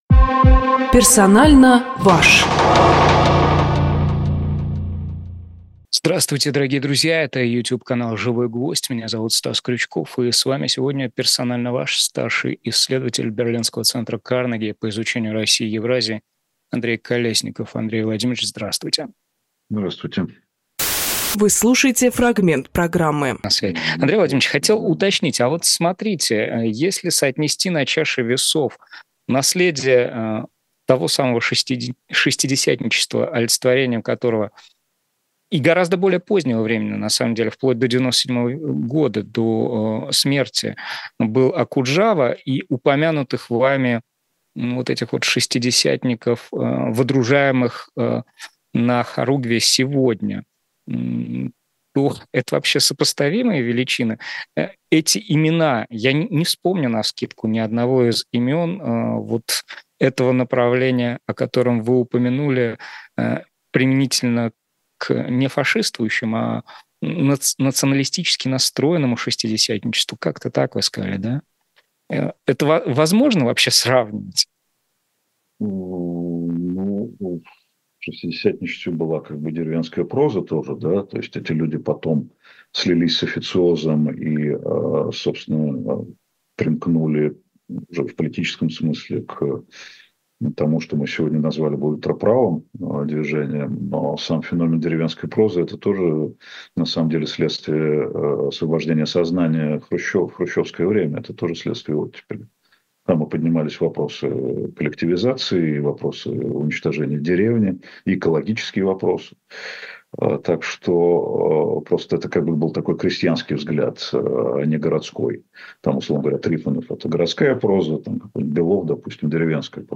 Фрагмент эфира от 06.05.24